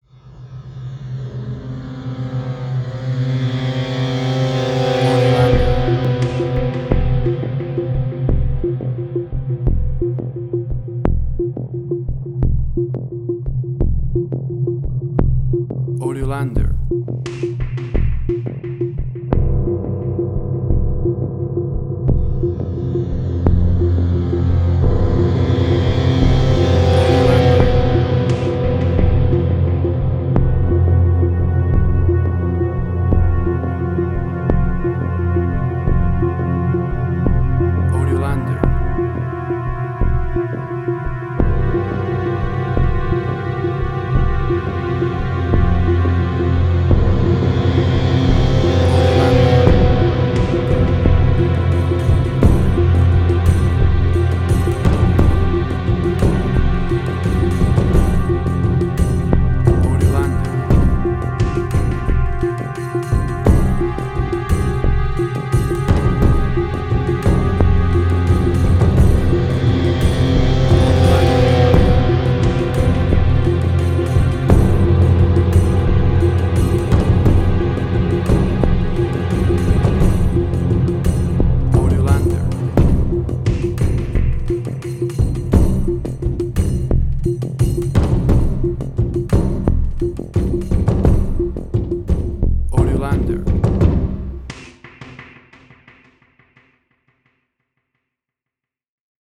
Dissonance
Fear.
Tempo (BPM): 87